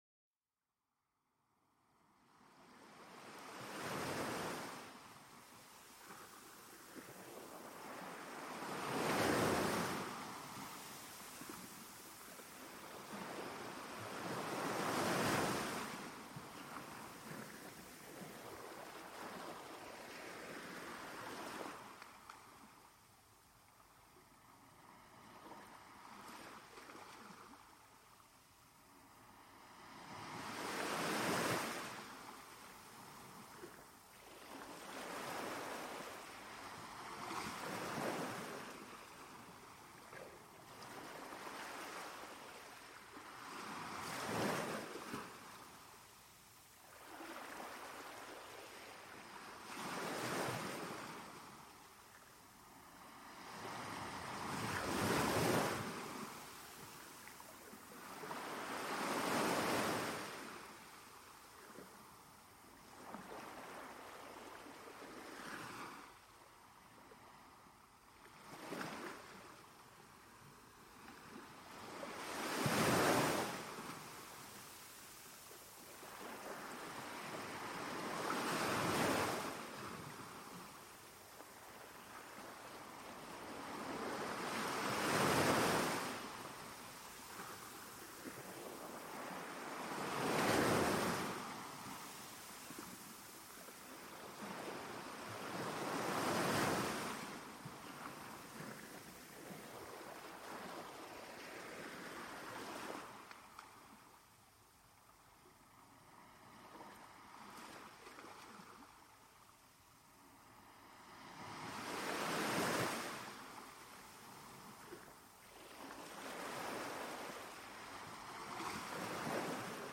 Relajación y Sueño con el Sonido de las Olas del Océano
Escucha el suave murmullo de las olas del océano para calmarte y relajarte. El sonido de las olas ayuda a calmar la mente y reducir el estrés.